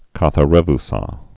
(käthä-rĕv-sä)